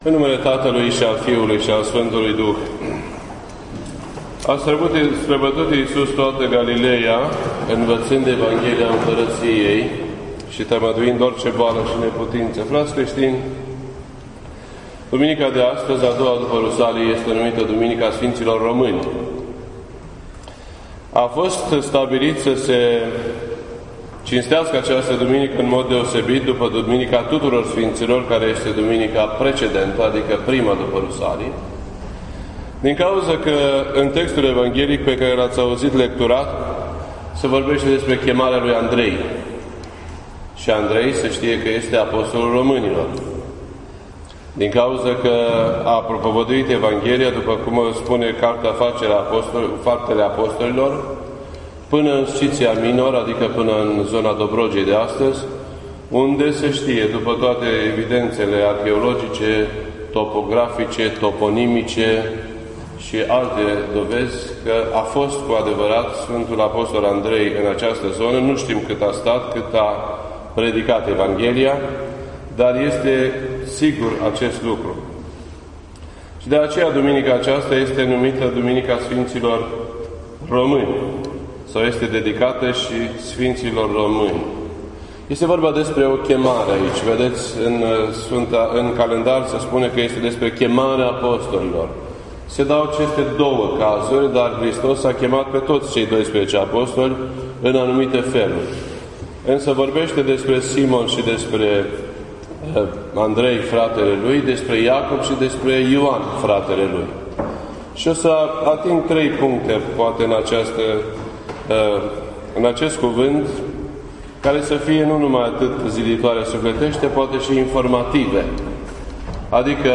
This entry was posted on Sunday, July 3rd, 2016 at 10:39 AM and is filed under Predici ortodoxe in format audio.